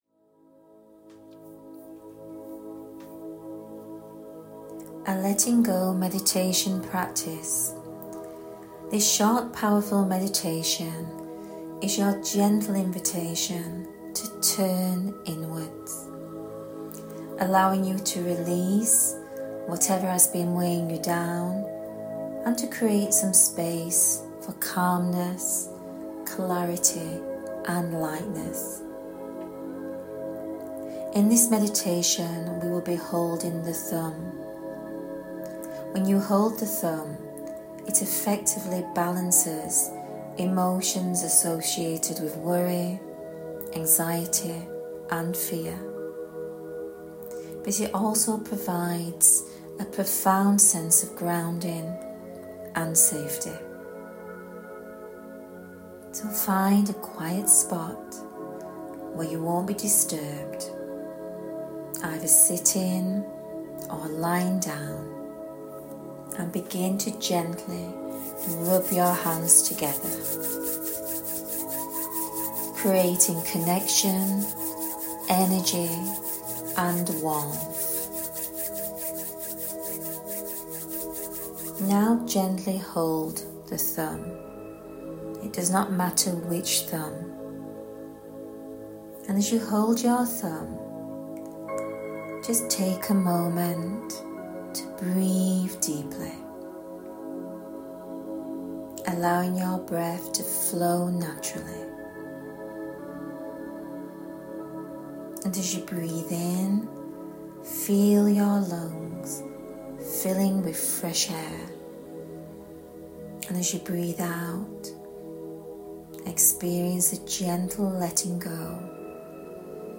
A Letting Go Practice Meditation 2.mp3